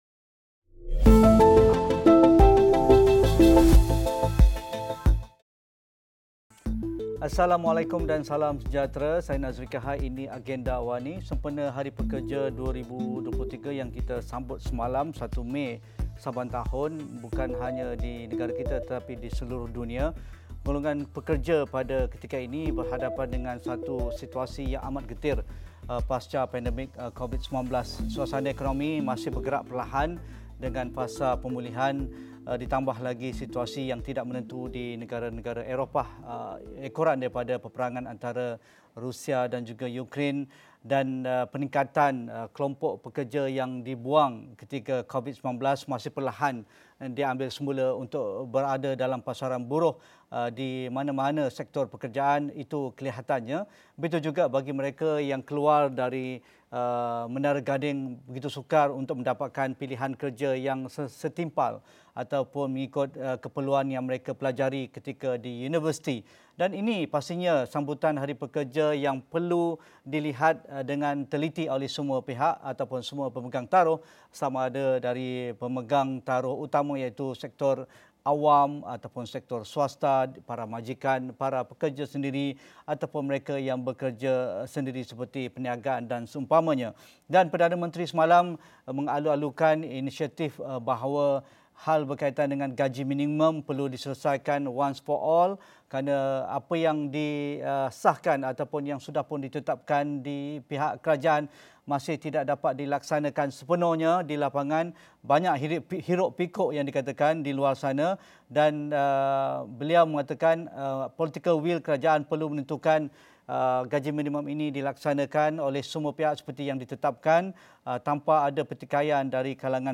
Diskusi 8.30 malam.